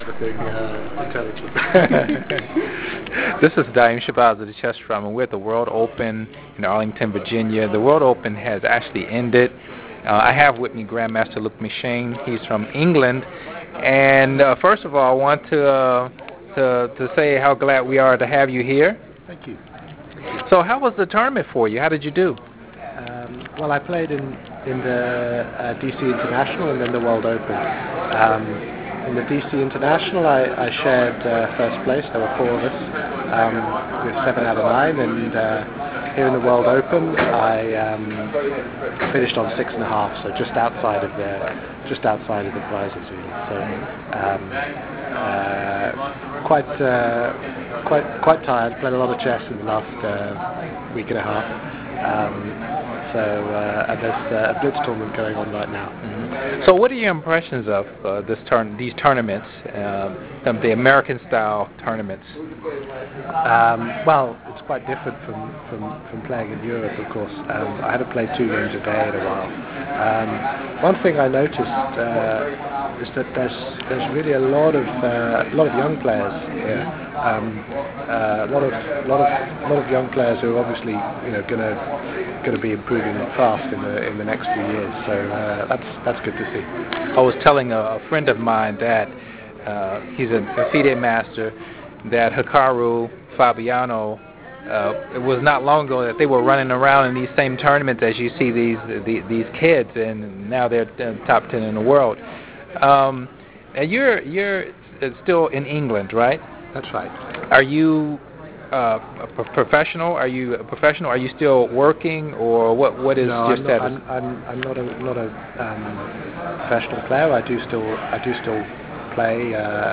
8. Drum Interviews @ ’15 World Open
GM Luke McShane (England) – Luke was playing in the blitz tournament when I asked for an interview so I only had a few minutes.